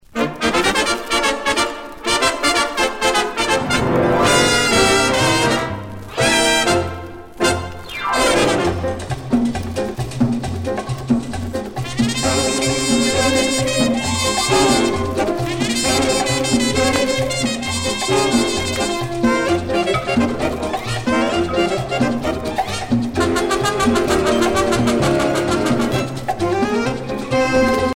danse : rumba